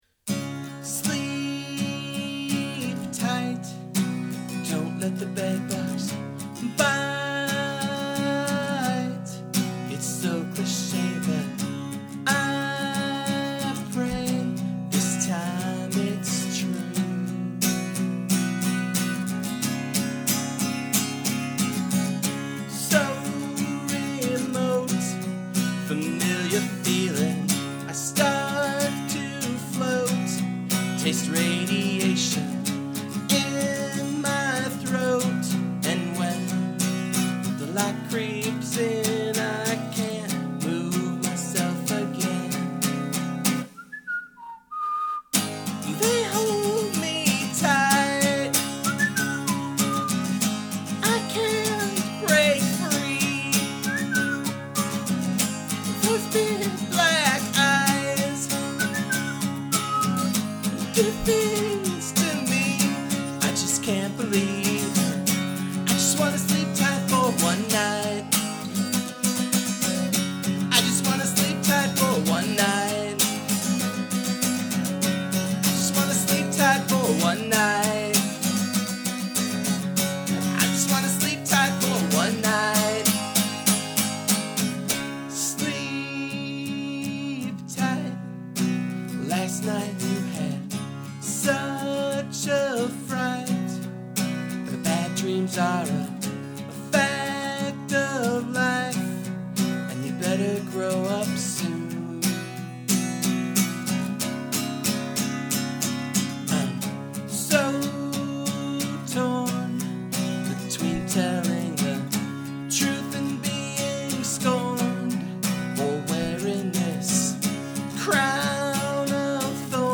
Song must include (human) whistling